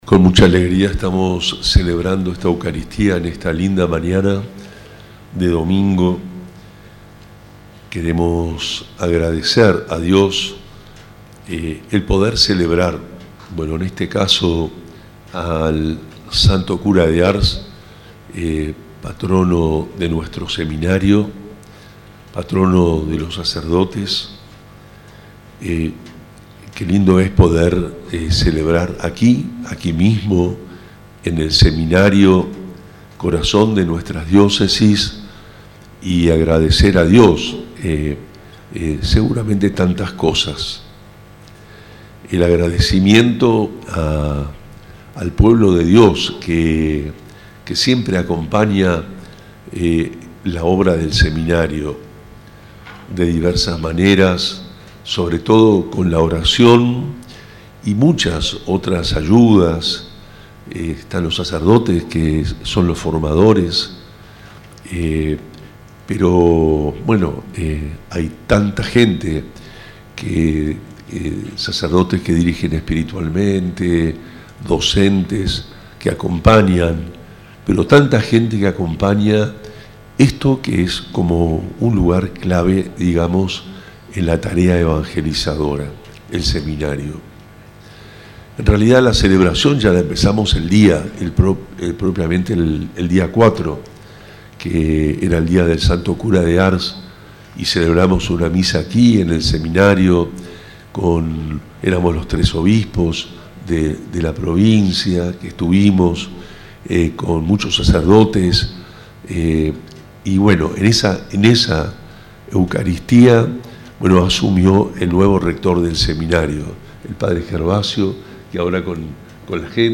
Presidida por el obispo de Posadas, monseñor Juan Rubén Martínez, la celebración tuvo lugar en el predio del Seminario y reunió a fieles, familiares de seminaristas y formadores. Radio Tupambaé y Canal 12 transmitieron la Eucaristía en vivo.